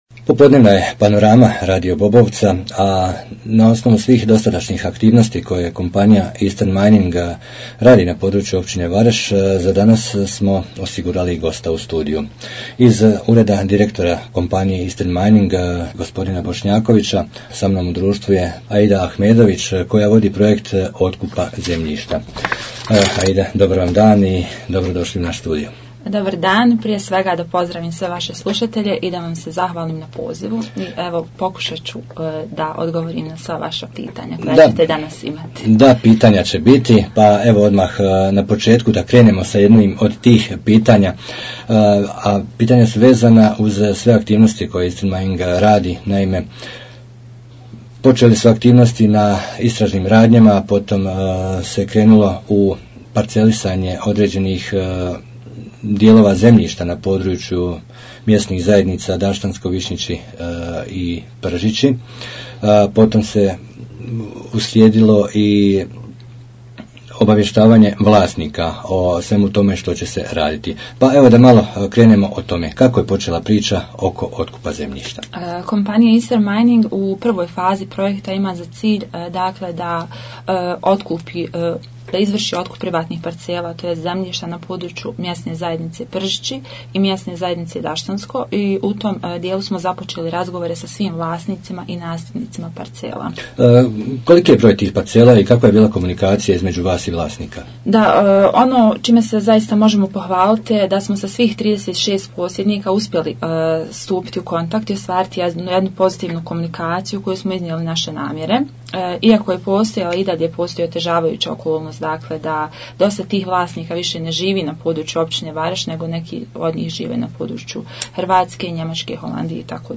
Eastern Mining o otkupu zemljišta - razgovor